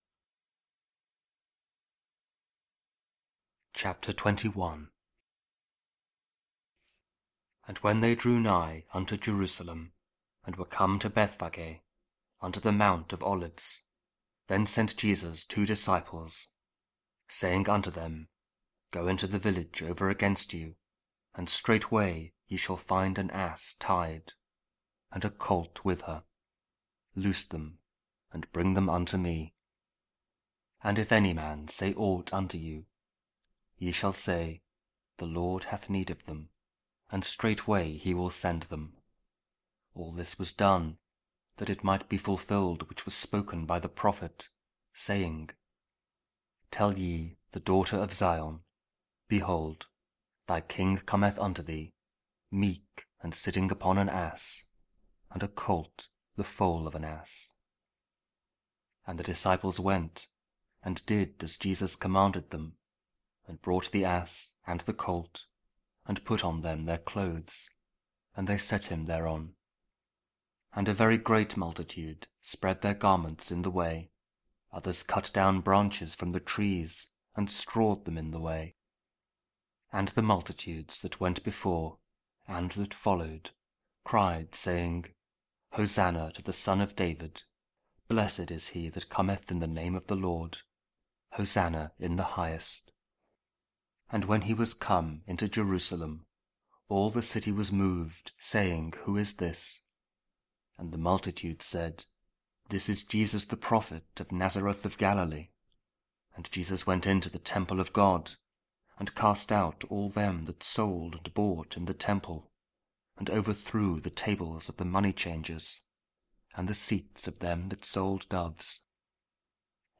Christian Art | King James Audio Bible KJV | Daily Bible Verses Advent